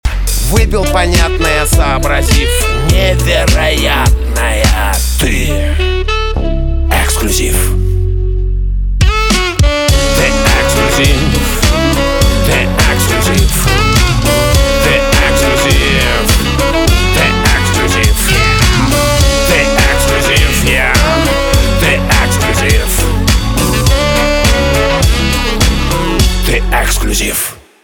русский рок
барабаны
труба , гитара